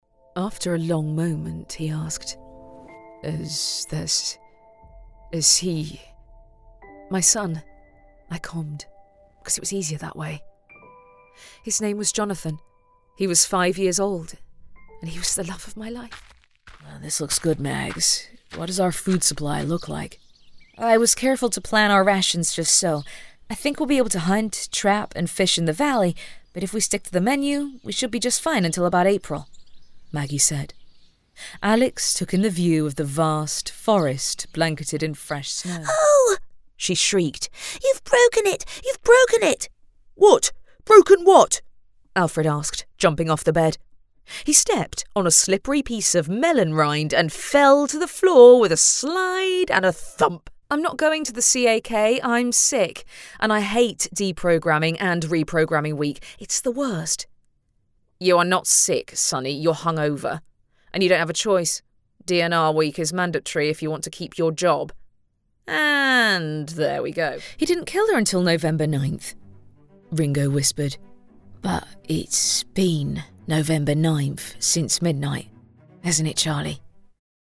Inglês (britânico)
Narração
Estúdio construído profissionalmente.
Mezzo-soprano